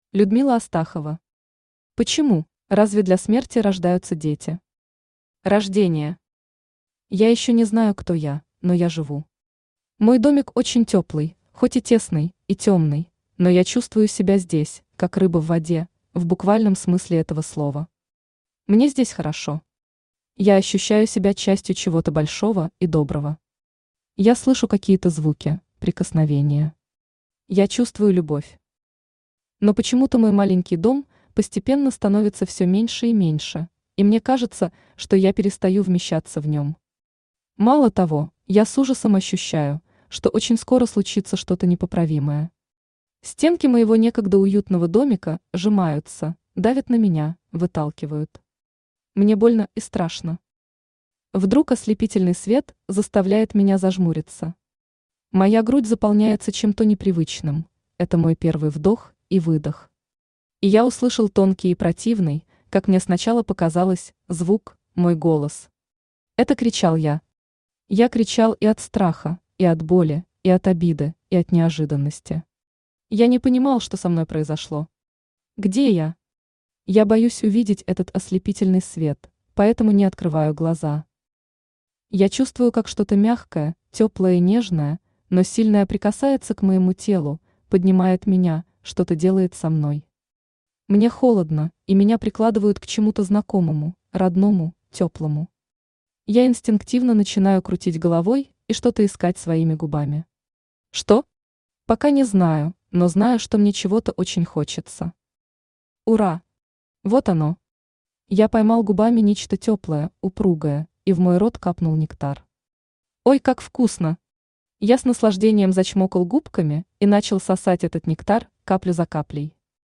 Aудиокнига Почему? Автор Людмила Астахова Читает аудиокнигу Авточтец ЛитРес.